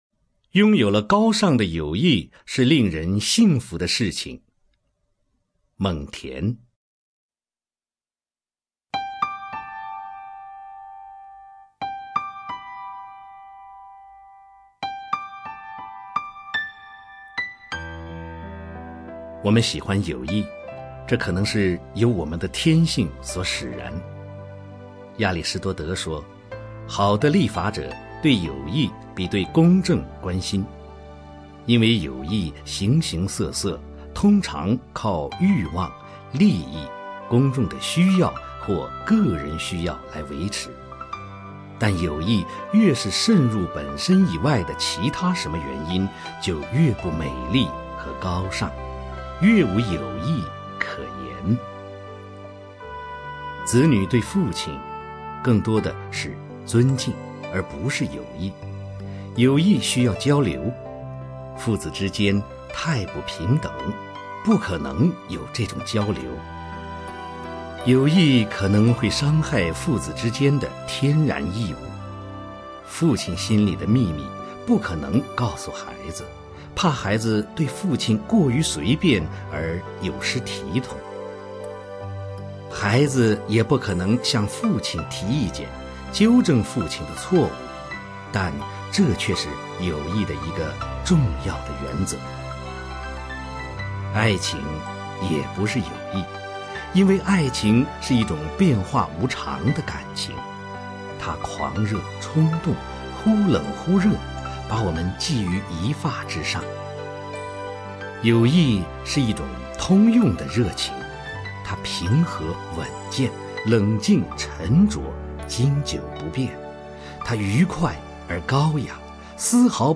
名家朗诵欣赏